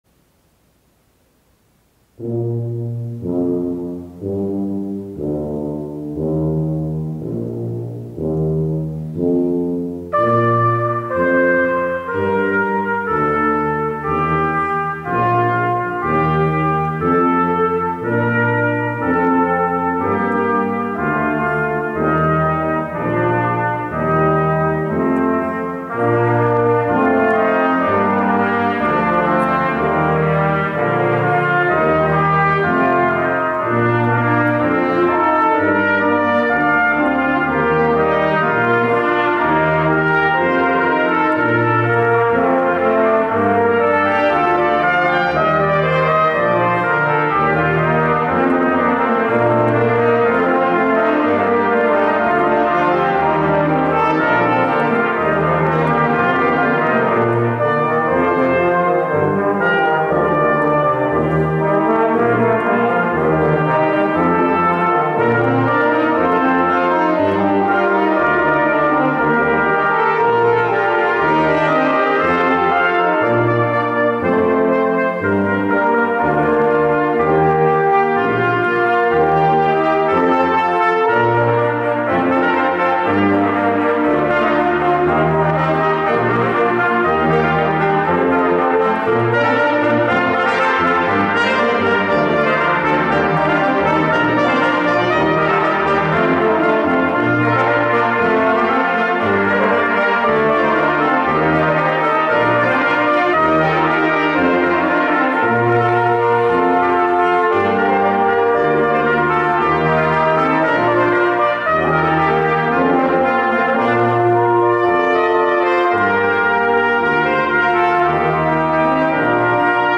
Formación musical profesional especializada en bodas, celebraciones privadas y conciertos en Málaga y Andalucía.
Ensemble Enarmonía es una formación musical elegante y versátil especializada en música en vivo para eventos en Málaga y Andalucía.